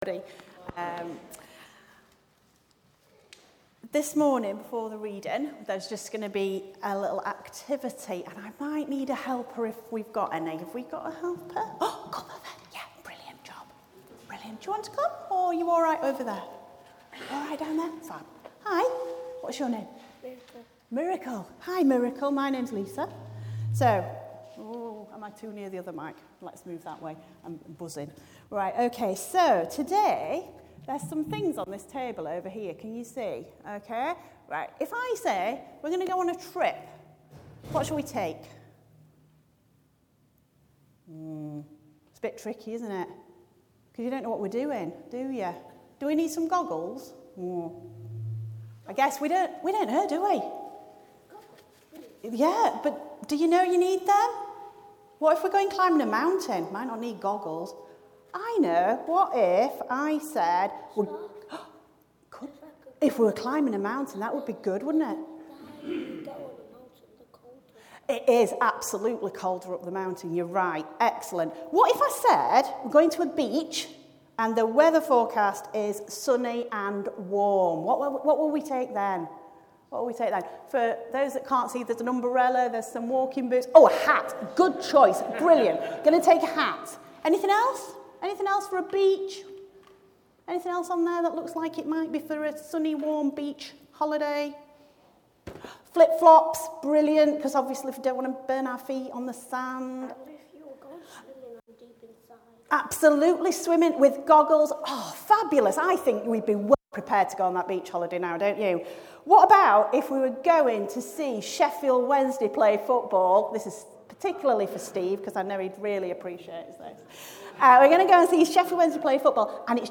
5. The Hated – from our sermon series on John